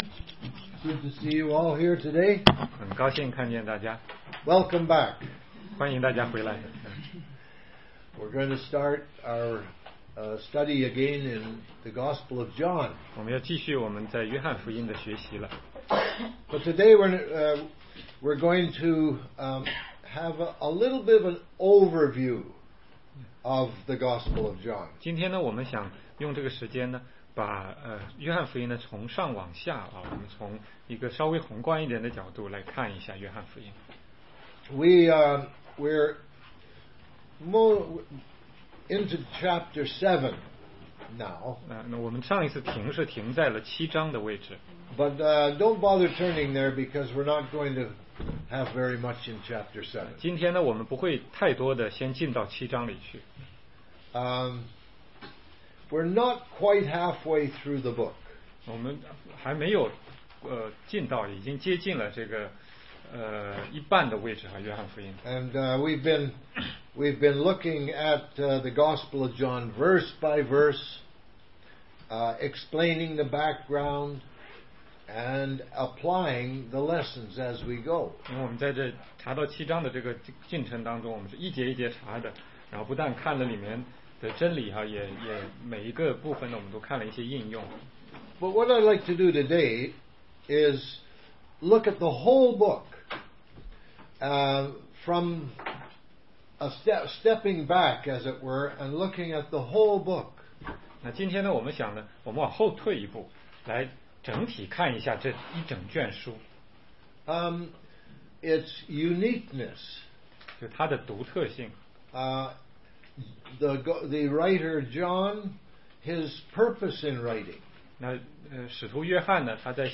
16街讲道录音 - 《约翰福音》概览